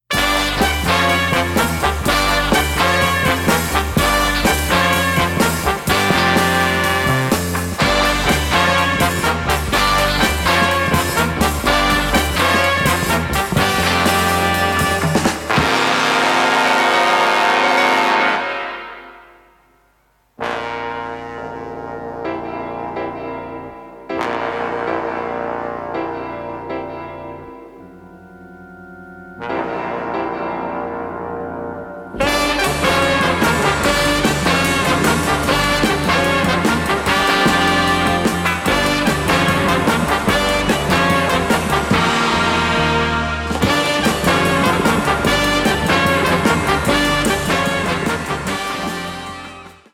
Der actionreiche, poppige Score